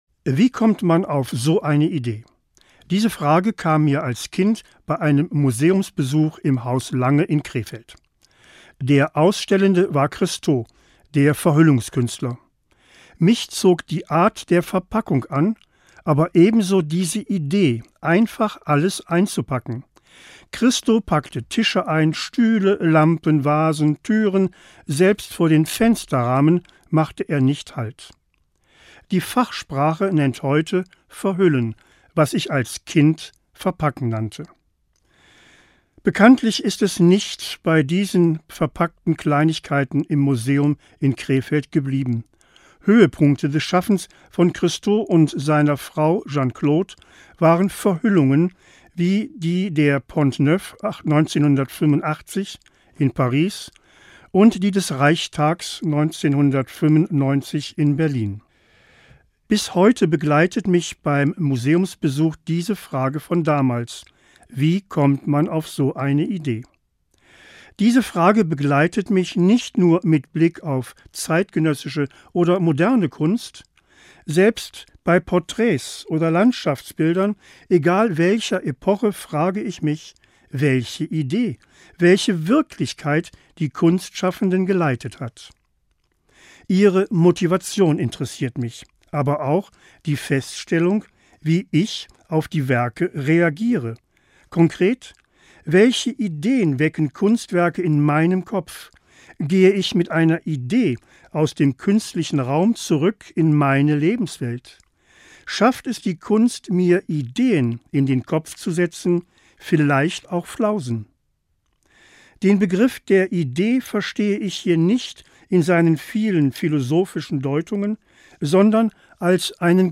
Morgenandacht 9.10.
Dieser Beitrag wurde in Radio-Ansprachen veröffentlicht.